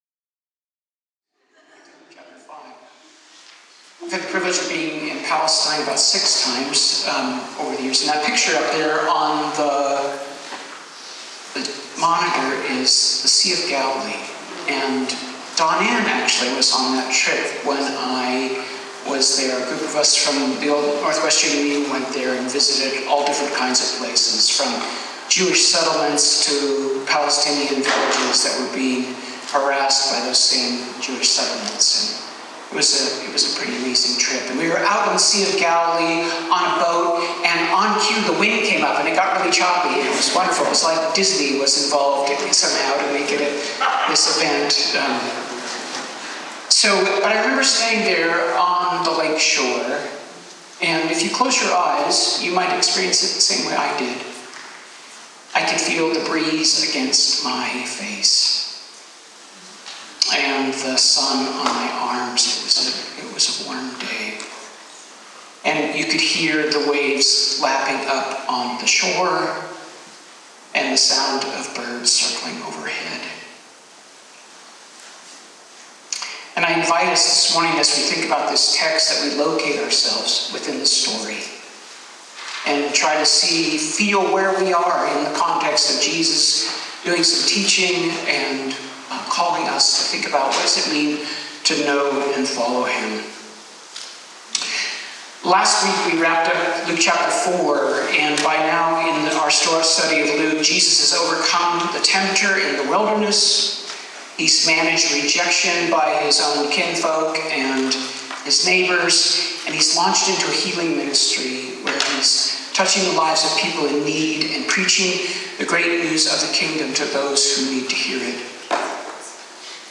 sermon series through the book of Luke, focusing on chapter 5, verses 1 through 11.